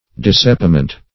Dissepiment \Dis*sep"i*ment\, n. [L. dissaepimentum, fr.